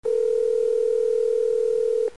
Telefono che squilla
Suono elettronico di telefono che squilla con tono continuo.